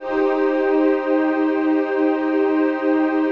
DM PAD2-17.wav